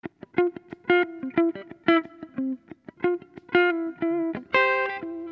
Sons et loops gratuits de guitares rythmiques 100bpm
Guitare rythmique 61